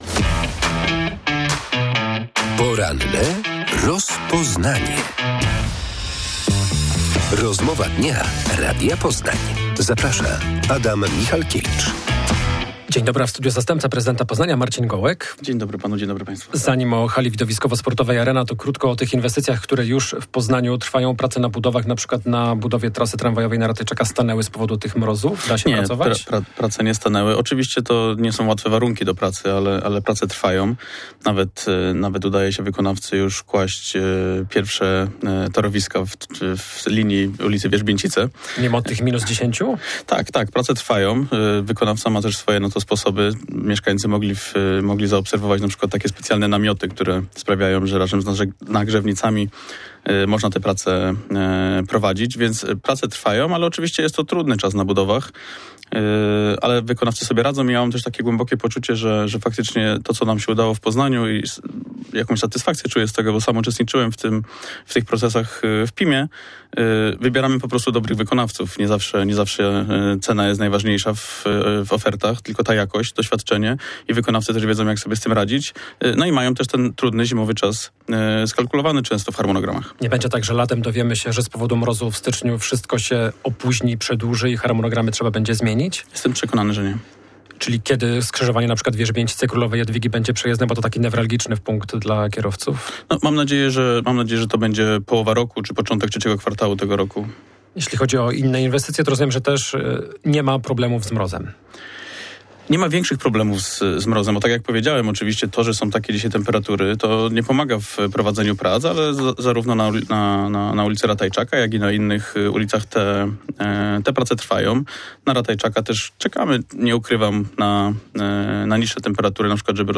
"Targi dadzą radę" - stwierdził dziś w porannej rozmowie Radia Poznań zastępca prezydenta Poznania Marcin Gołek. Jego zdaniem, Międzynarodowe Targi Poznańskie samodzielnie poradzą sobie z zapowiadanym od lat remontem hali Arena.